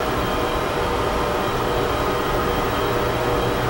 SFX / Ambient / Room ambience / servers1.ogg
servers1.ogg